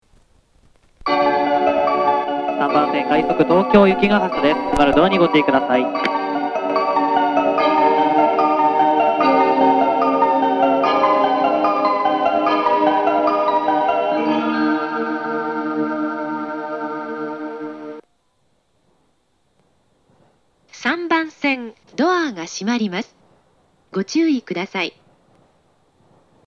■高尾駅　ホームの様子（3・4番線西八王子方から撮影）
発車メロディー
フルコーラスです。
快速電車より中電の方が2コーラス目に入りやすいです。